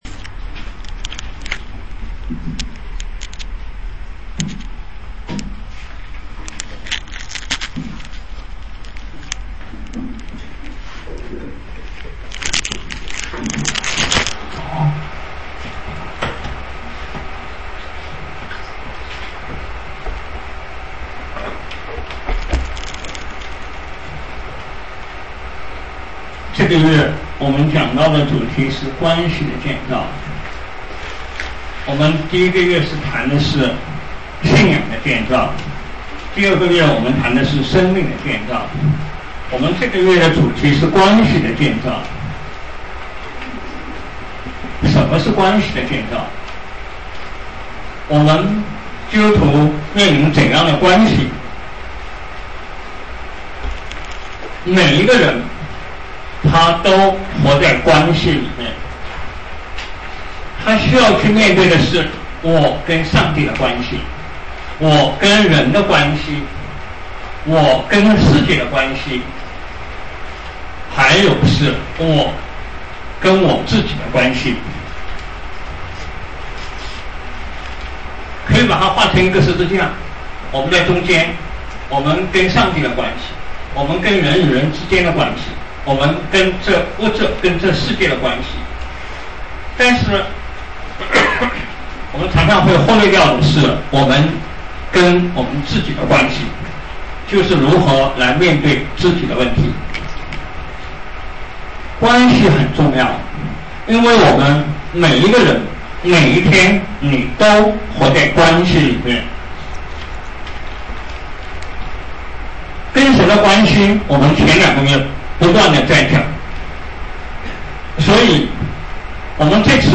讲章：关系的建造——面对自我（2007年5月20日，附音频）